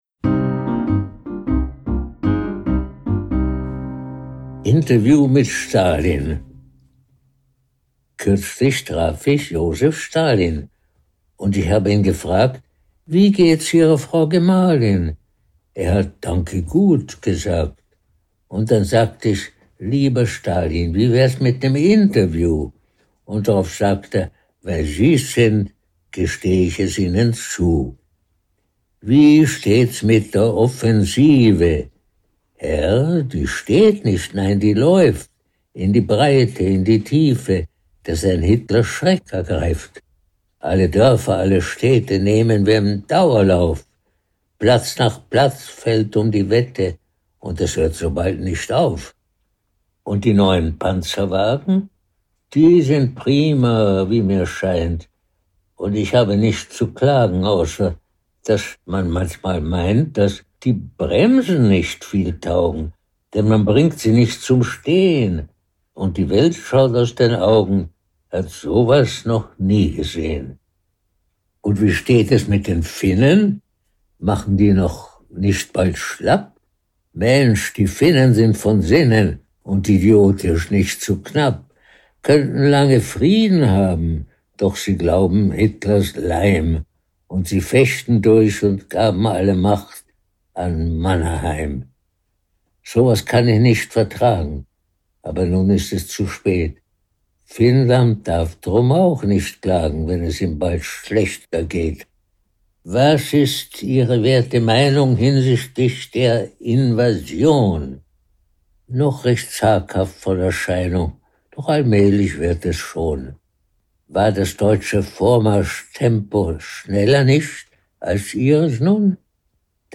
performed by Georg Stefan Troller
Stefan-Troller_Interview-mit-Stalin-mit-Musik.m4a